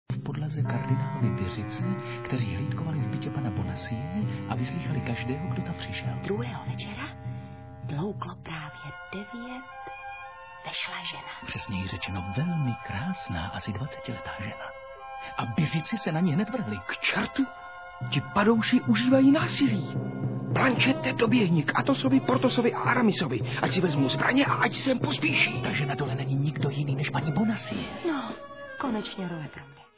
• zvuk: Stereo